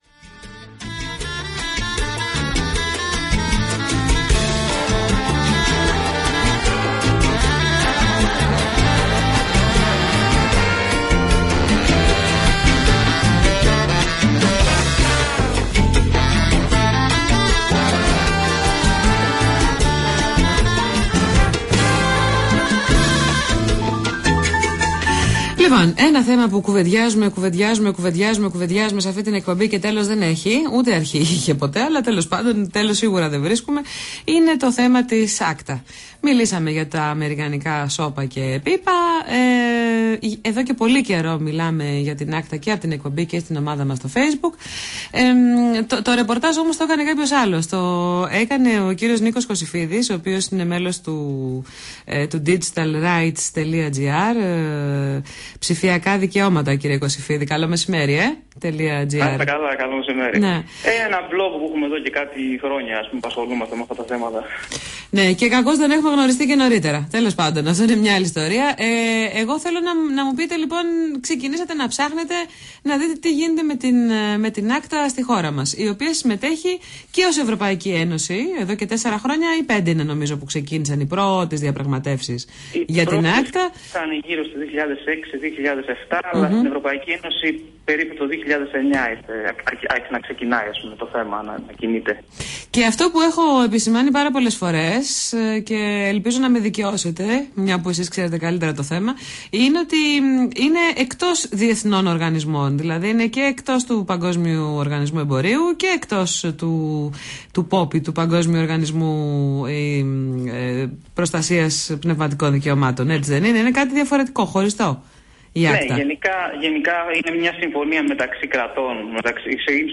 Συνέντευξη
στο Ραδιοφωνικό Σταθμό NET FM